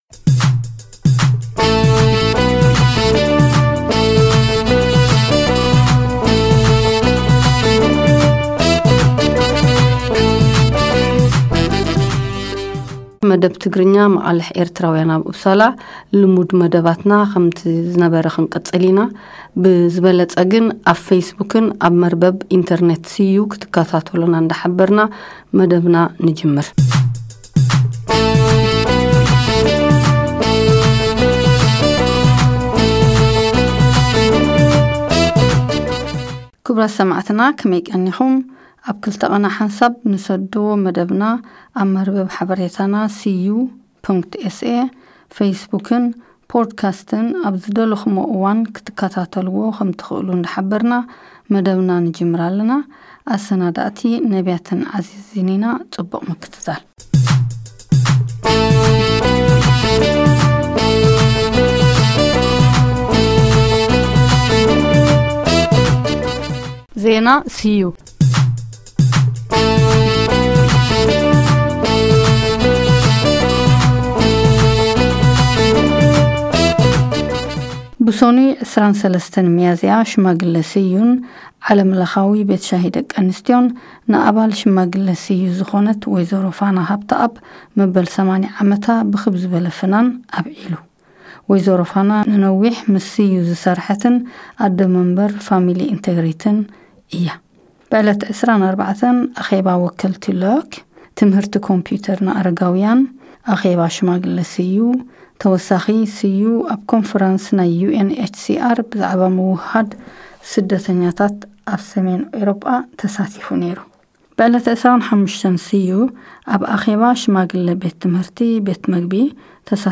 ትሕዝቶ መደብና ዜና ኡፕሳላ ፥ ዜና ሃገር፥ ሓበሬታን ጥዑማት ዜማታትን አዩ ።